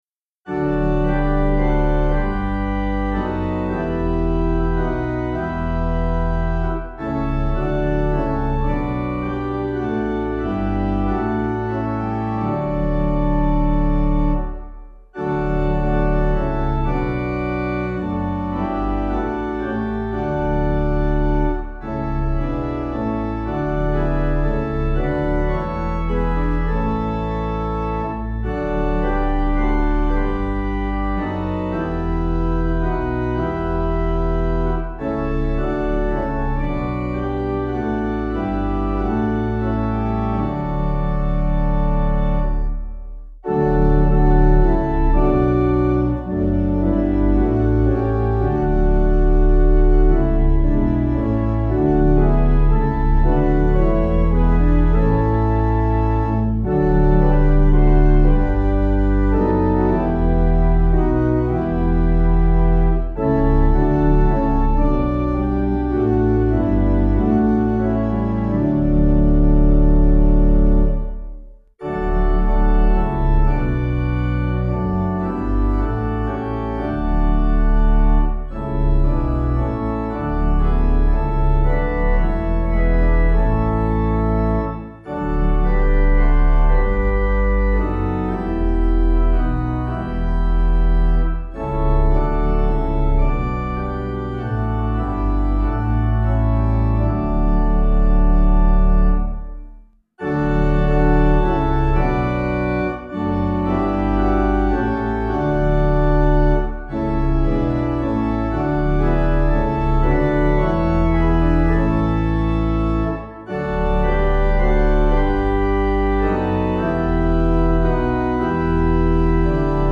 Meter: 8.8.8.8
Key: D Major